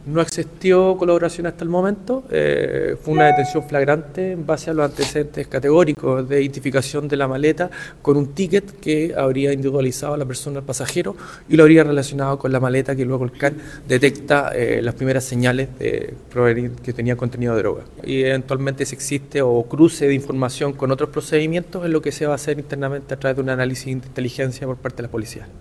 Se están determinando las conexiones que puedan existir con redes de narcotráfico en la Región de Los Lagos, lo que hasta ahora no ha dado resultados pues pese a la flagrancia del delito el detenido no ha colaborado con la investigación, como señaló el fiscal Montero.